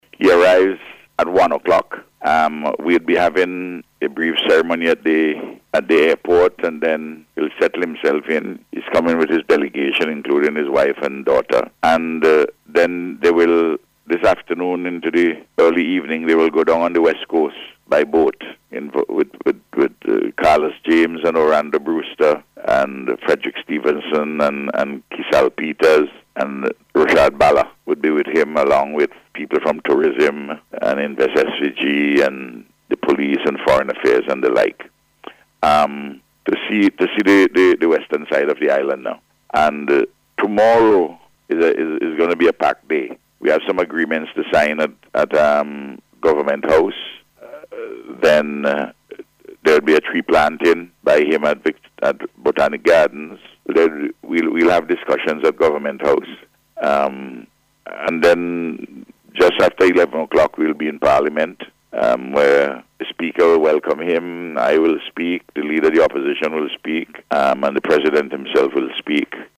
Speaking on NBC’s Face to Face programme this morning, the Prime Minister highlighted some of the events planned for the visit.